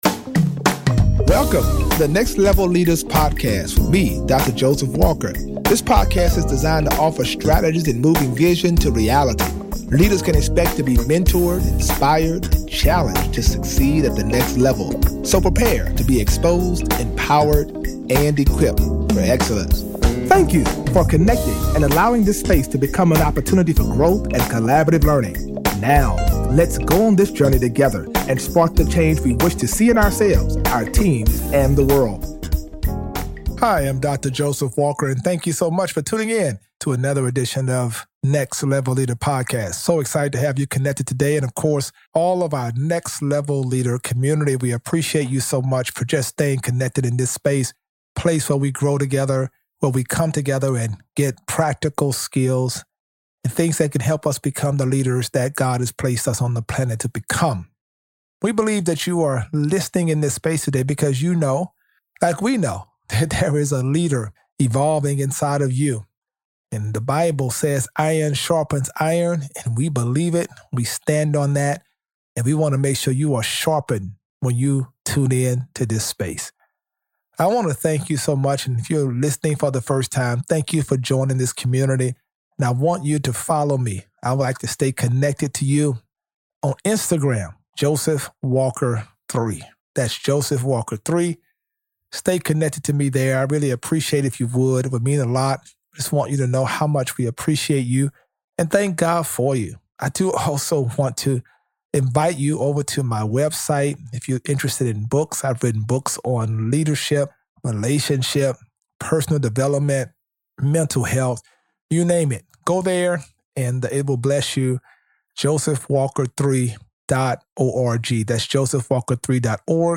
Each episode addresses the intersect between Christianity and the marketplace through conversations with successful leaders. Listeners will be mentored, inspired, and challenged to succeed at the next level.